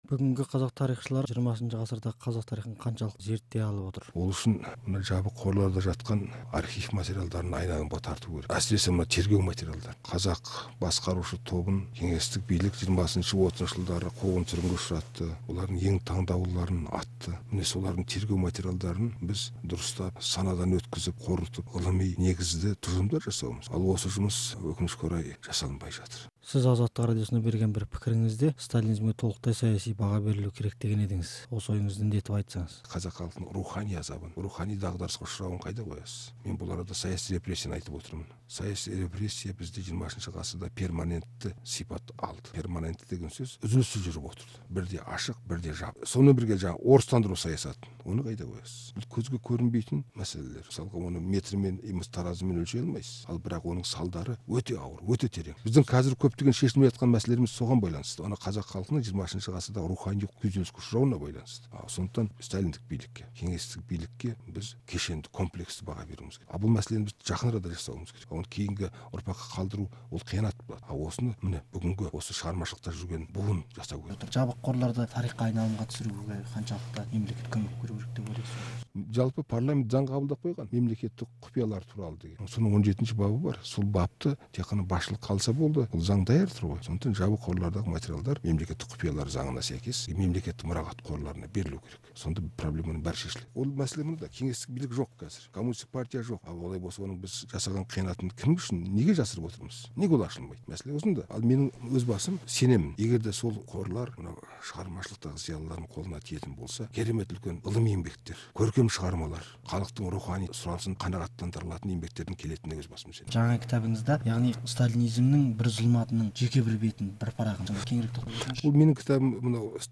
Мәмбет Қойгелдімен сұхбатты тыңдаңыз